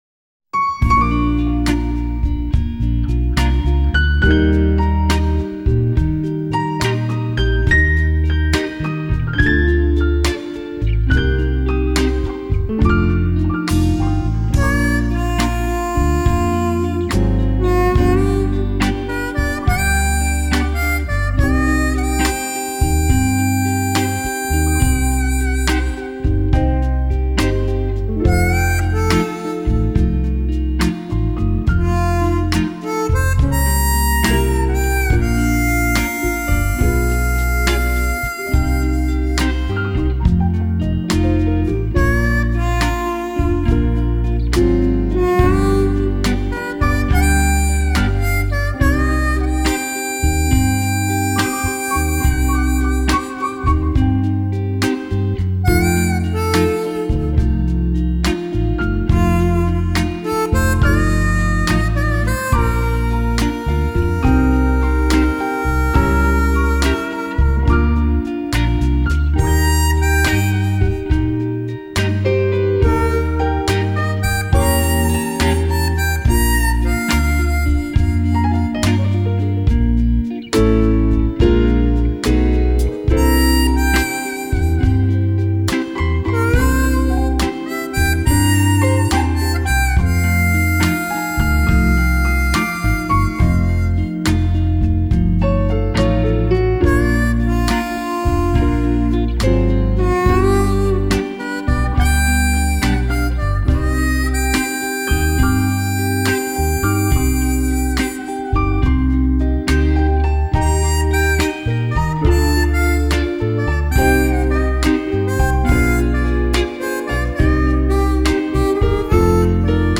浪漫动人的经典乡村情歌以纯音乐的形式为你深情演绎！